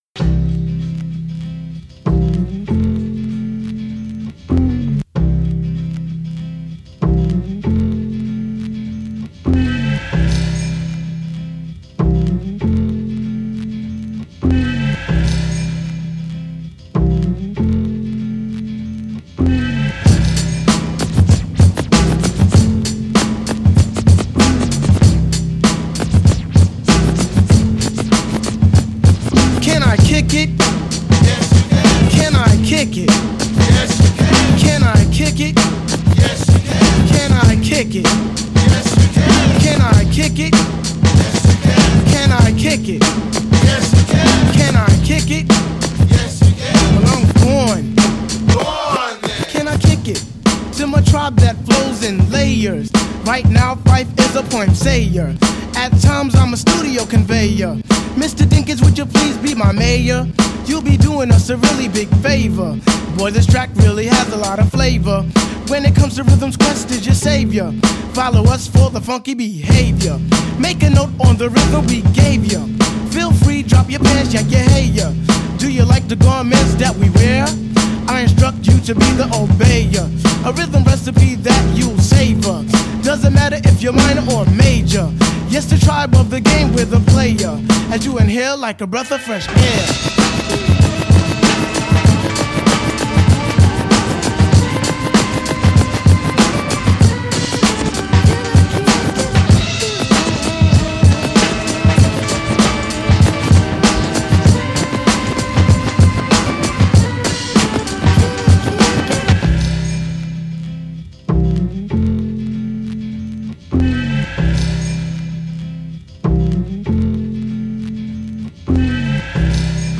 questo mix di pezzi